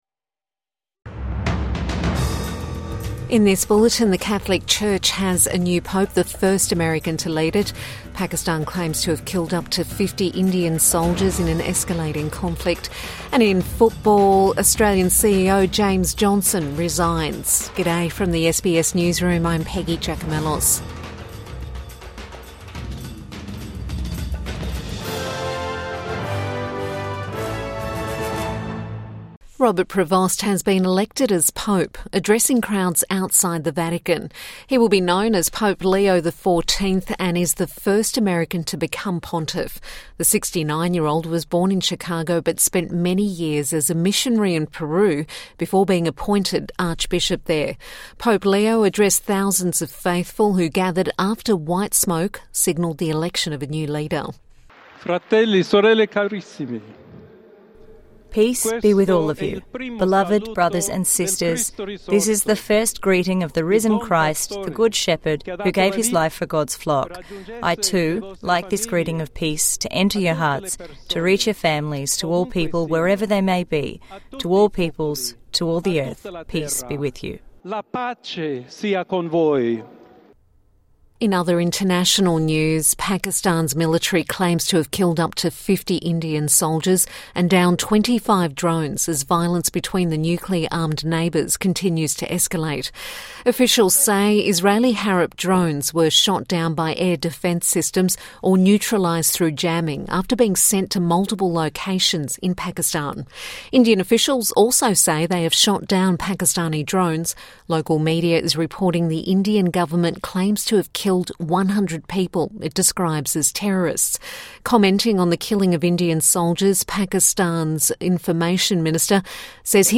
The Catholic Church has a new pope | Morning News Bulletin 9 May 2025